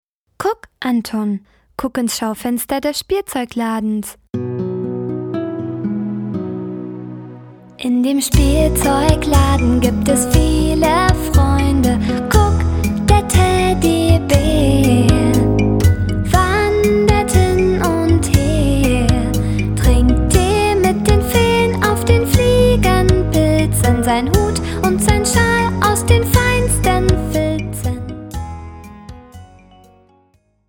Personalisierte Kinderlieder für den ganzen Tag.
Etwas ruhiger wird es dann